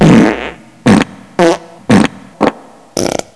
6farts
6farts.wav